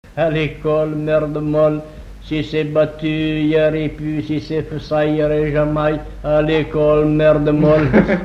formulette enfantine : amusette
Pièce musicale inédite